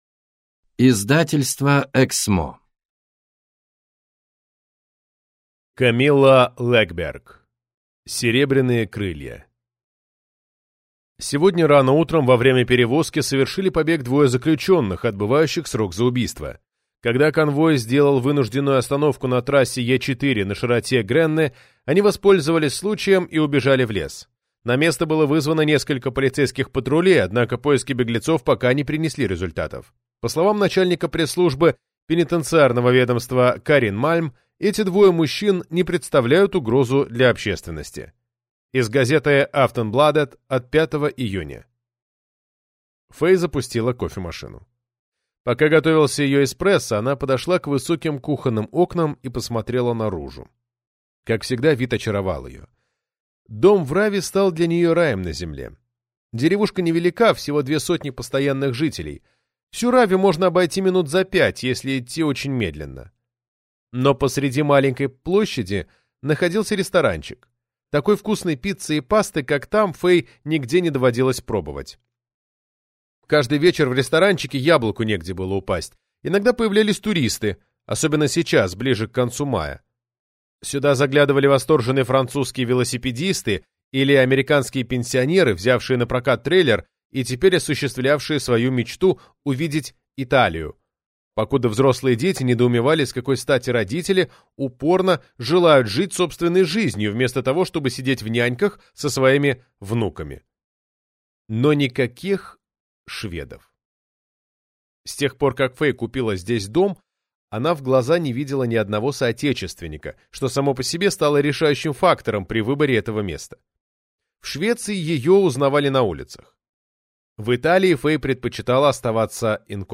Аудиокнига Серебряные крылья | Библиотека аудиокниг
Прослушать и бесплатно скачать фрагмент аудиокниги